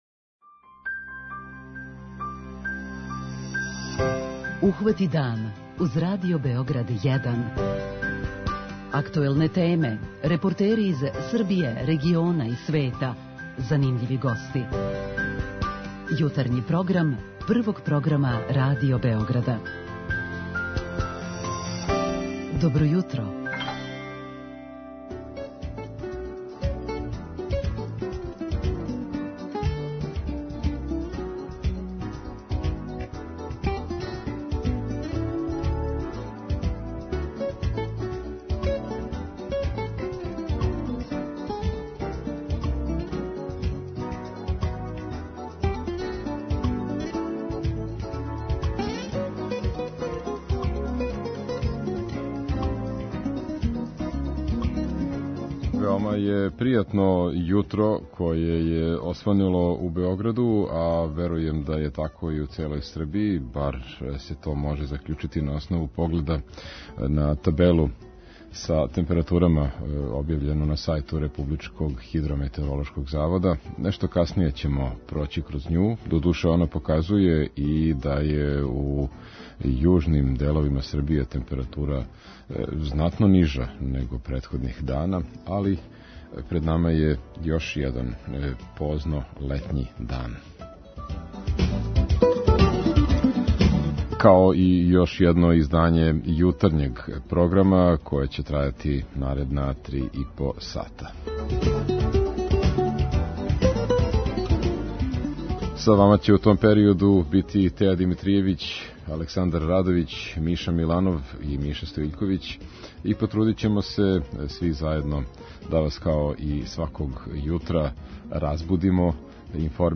На ову тему разговараћемо прво са слушаоцима у нашој редовној рубрици 'Питање јутра', а потом и са гостима.